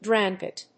drain+pit.mp3